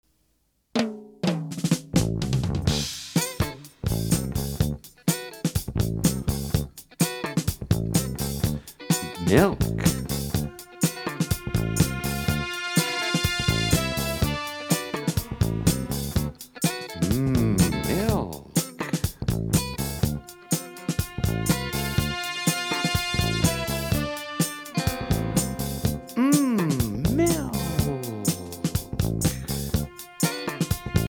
clapping rhymes, playground games and traditional camp tunes